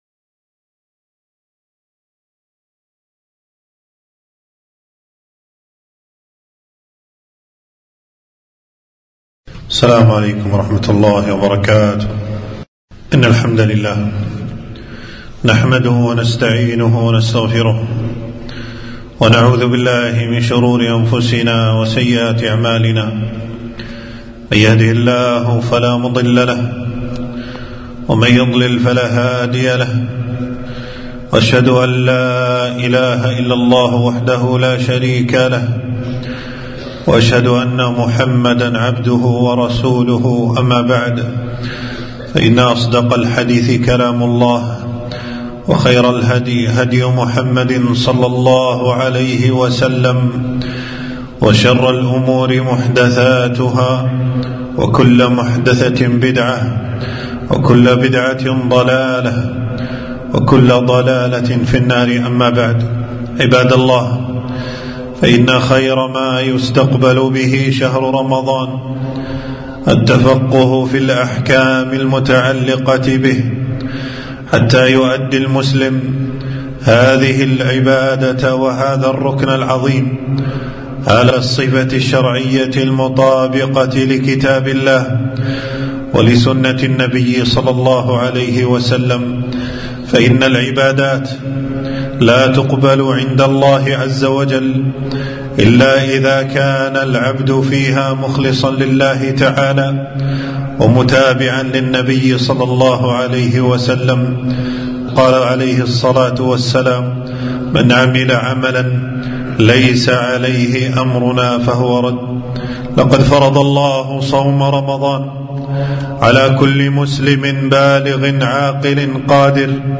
خطبة - المختصر في أحكام الصيام